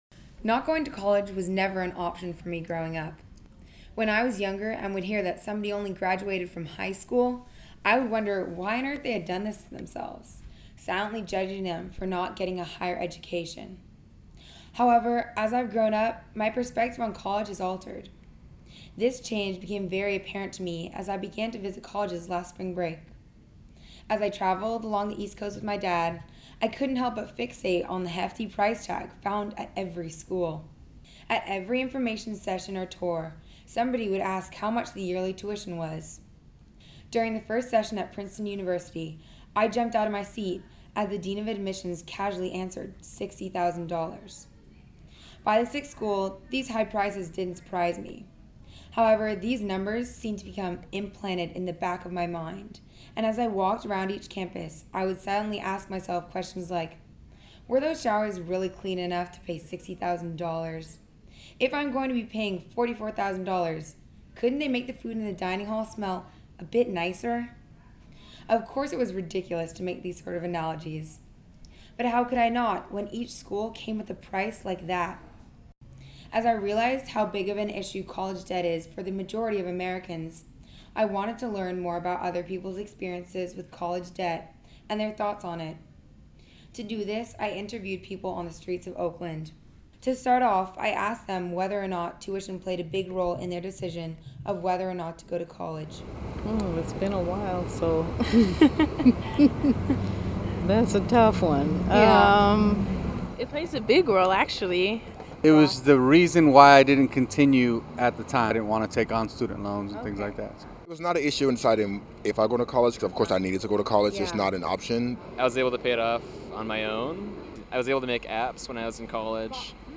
As I realized how big of an issue college debt is for the majority of Americans, I wanted to learn more about other people’s experiences with college debt and their thoughts on it. To do this, I interviewed people on the streets of Oakland.
College-Vox-Pop.mp3